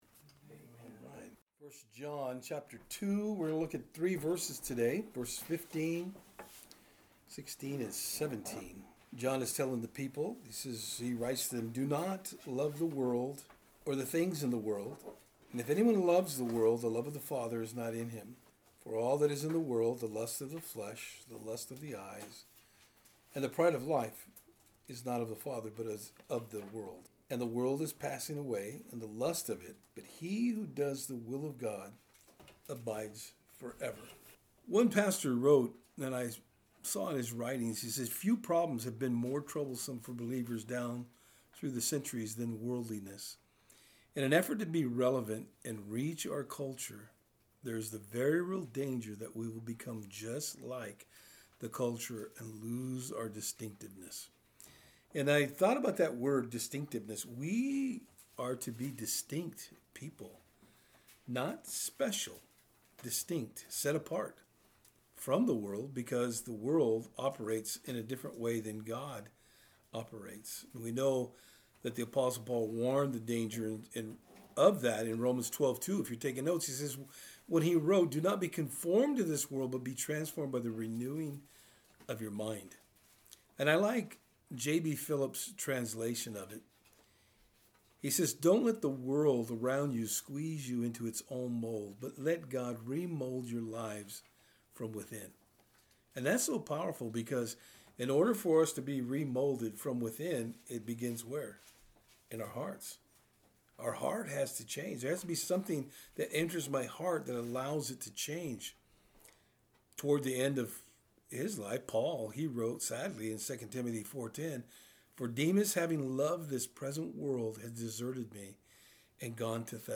1 John 2:15-17 Service Type: Thursday Eveing Studies The Apostle John gives us the admonition to steer clear of the worlds ideology.